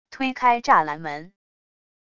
推开栅栏门wav音频